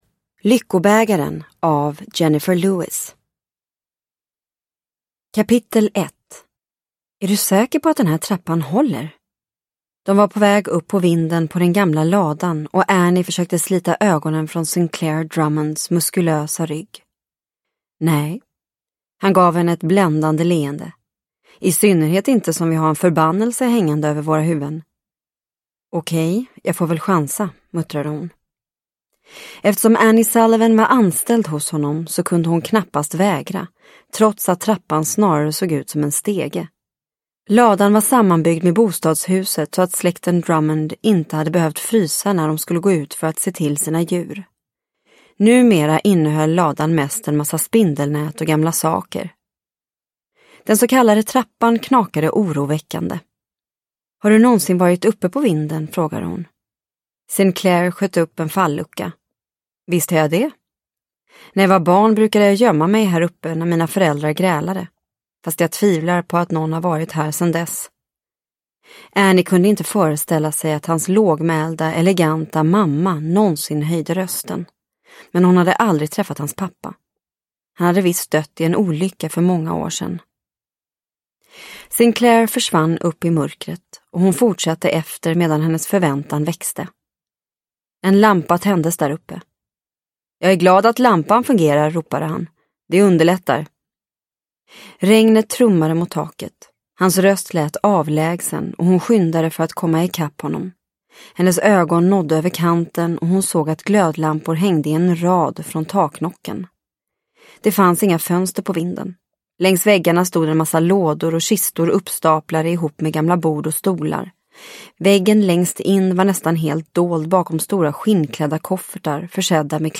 Lyckobägaren – Ljudbok – Laddas ner